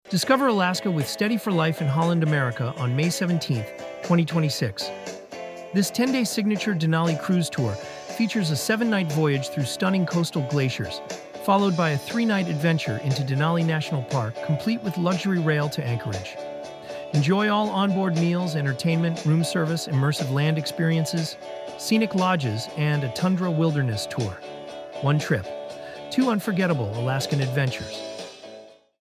3.-May-17-Holland-America-version-2-with-music.mp3